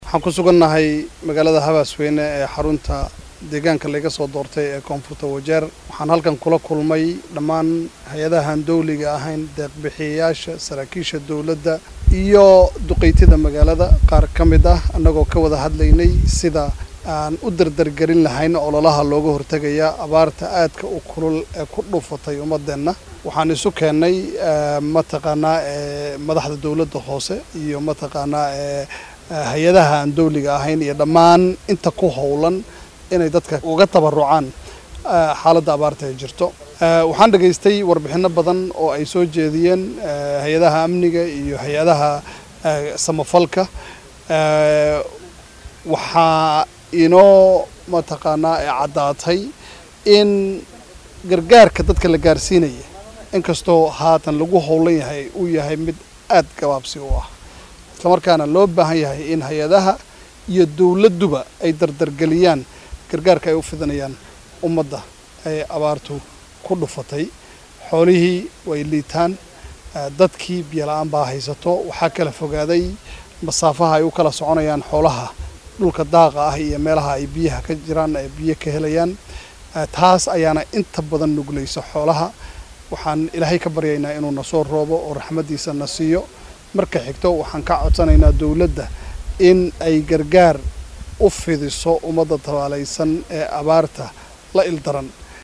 Xildhibaanka laga soo doortay Koonfurta Wajeer Maxamad Cadow ayaa warbaahinta Star faahfaahin ka siiyay shirka halkaasi ka qabsoomay.